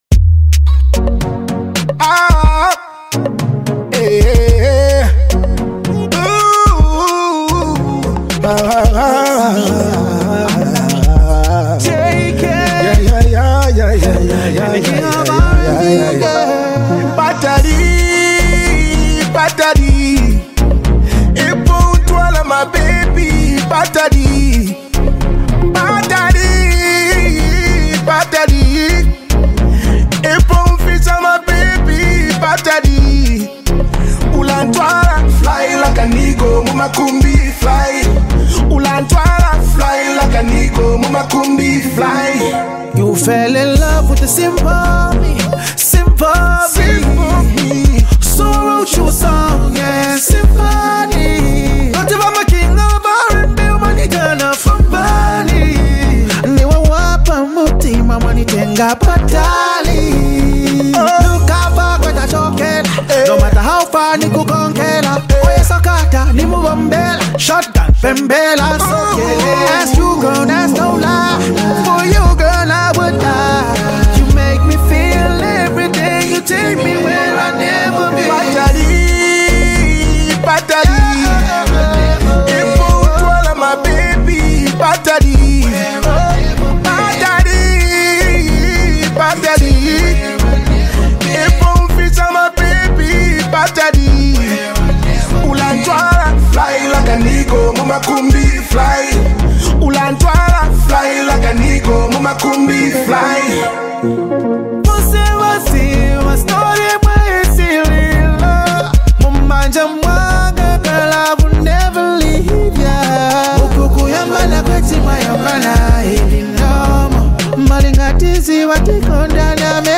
RnB track